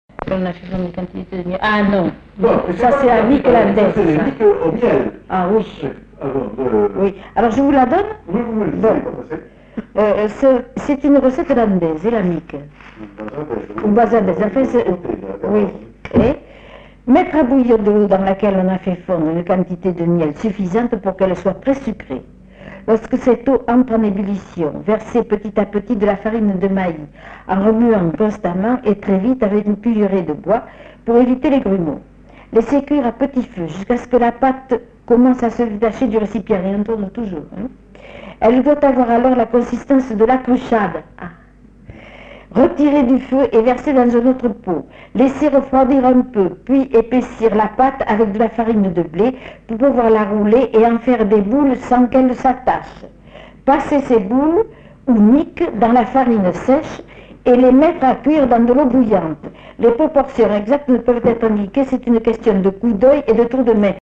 Aire culturelle : Bazadais
Lieu : La Réole
Genre : témoignage thématique